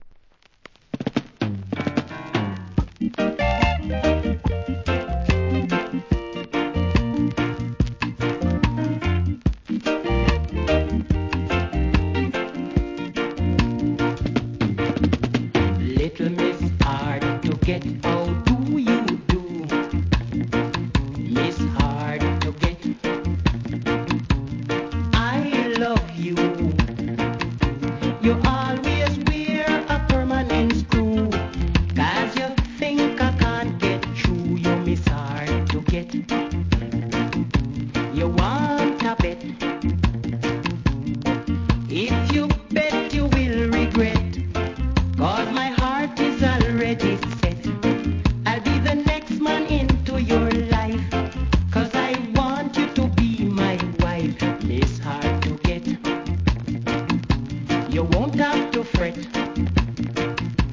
REGGAE
味のあるGOOD VOCAL!!